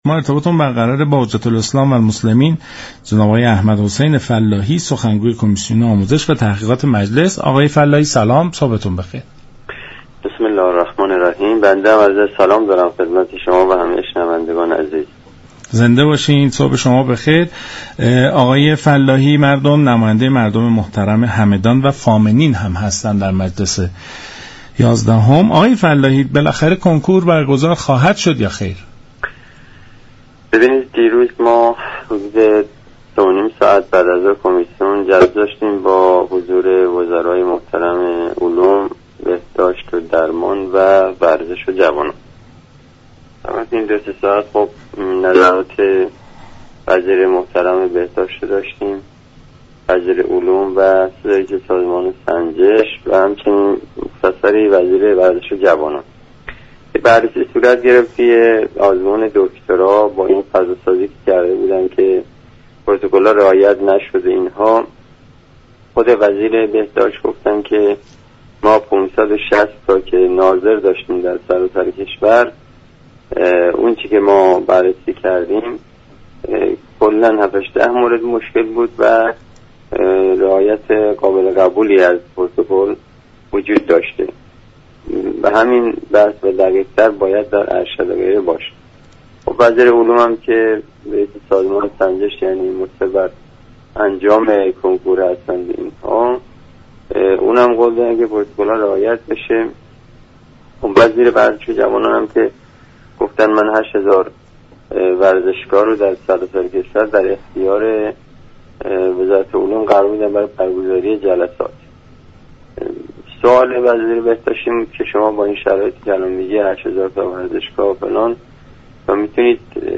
به گزارش شبكه رادیویی ایران، حجت الاسلام والمسلمین احمد حسین فلاحی سخنگوی كمیسیون آموزش و تحقیقات مجلس در برنامه سلام صبح بخیر رادیو ایران از جلسه روز گذشته ( 12 مرداد 99) كمیسیون كه با حضور وزرای علوم، بهداشت و درمان و ورزش و جوانان و رئیس سازمان سنجش برگزار شد خبر داد : این جلسه كه حدود 3 ساعت به طول انجامید ساز و كارهای برگزاری كنكور مورد بررسی قرار گرفت.